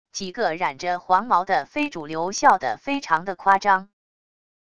几个染着黄毛的非主流笑的非常的夸张wav音频